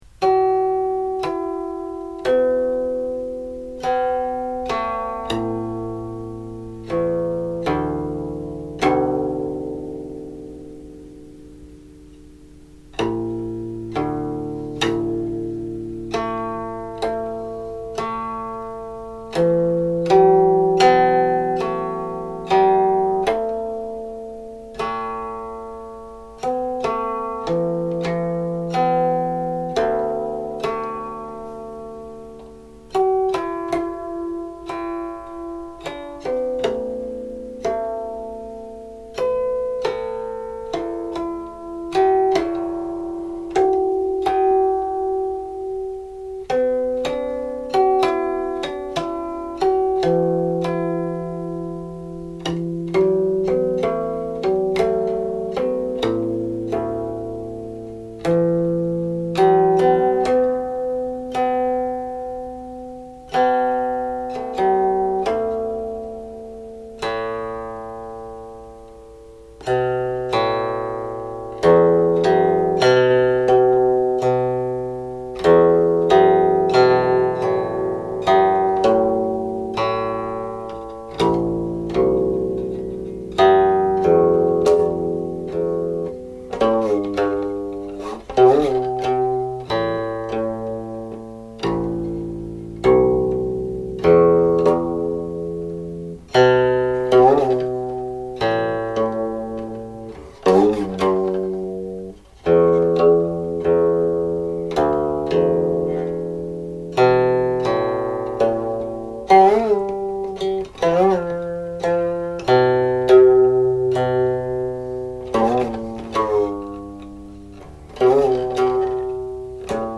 Grouped under Gong mode2
The nature of the lyrics suggests that at least some of them were created specifically to be sung.
00.00 Closing harmonics, used here as a prelude